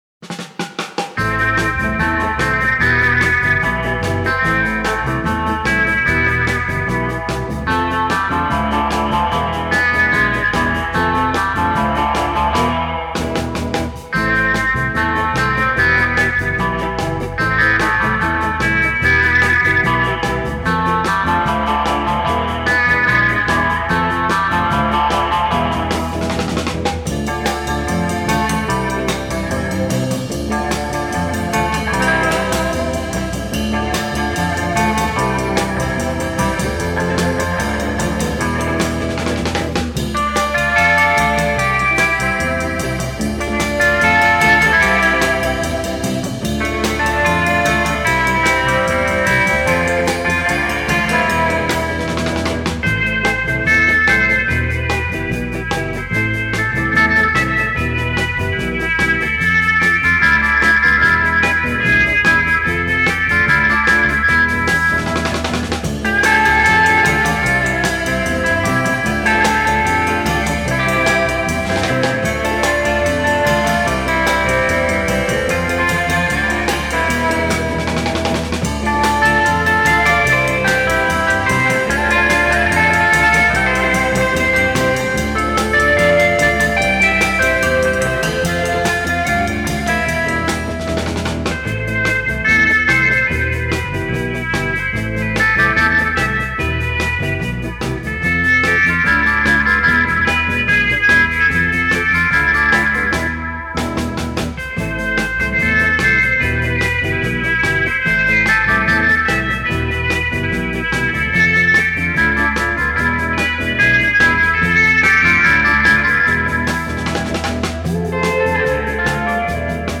инструментальная группа 60-х годов XX века.